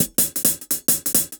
Index of /musicradar/ultimate-hihat-samples/170bpm
UHH_AcoustiHatA_170-04.wav